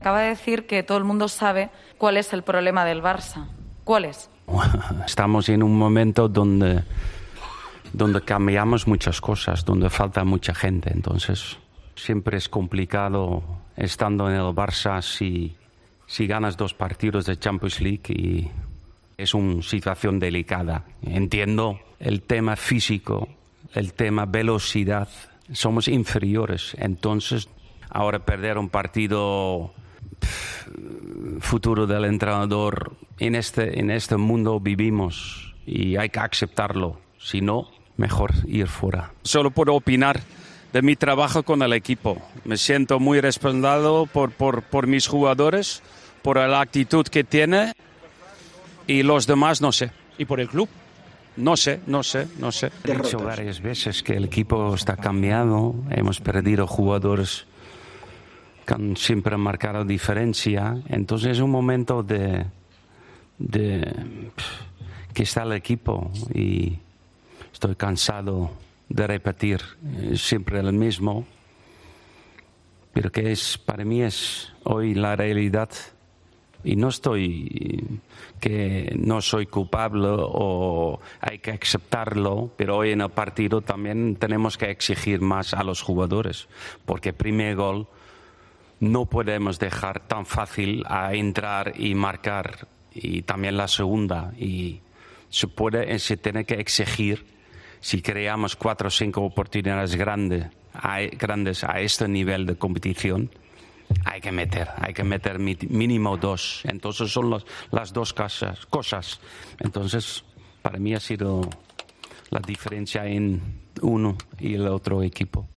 AUDIO: El técnico holandés ha asegurado en los micrófonos de Movistar tras la derrota ante el Benfica que siente que tiene el apoyo de sus futbolistas a pesar...